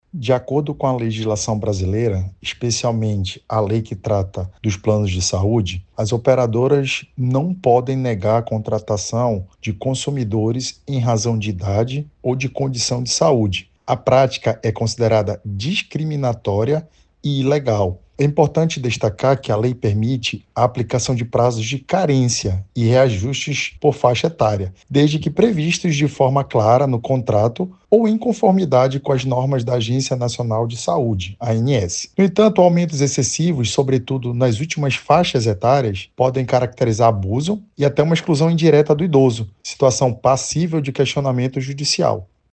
SONORA-01-ADVOGADO-.mp3